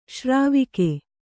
♪ śrāvike